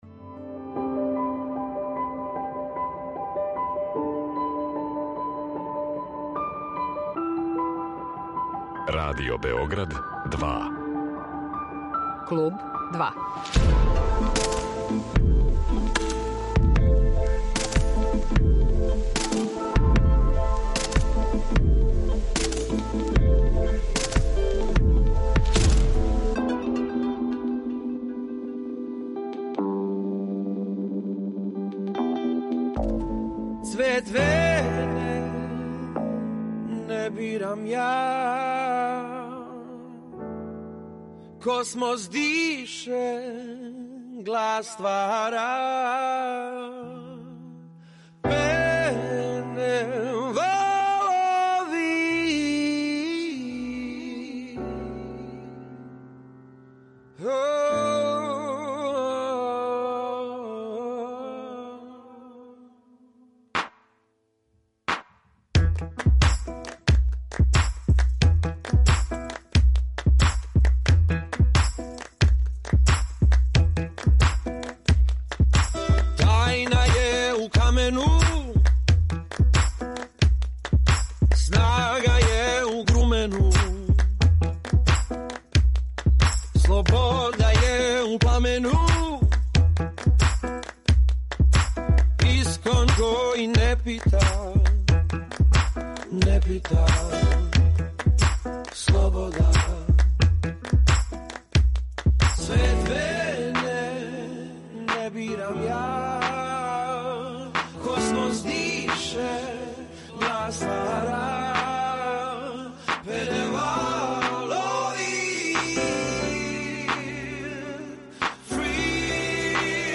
Клуб 2: Mузичар Марко Луис